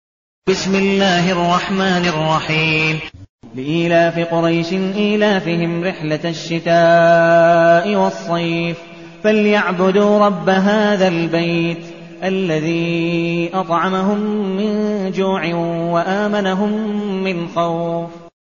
المكان: المسجد النبوي الشيخ: عبدالودود بن مقبول حنيف عبدالودود بن مقبول حنيف قريش